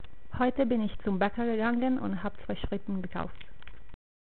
The volume is quite low, I hope you can hear something.
Is it just me or does my voice sound different when I speak in different languages? o_O